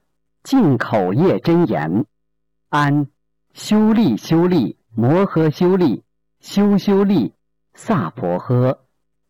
001《净口业真言》教念男声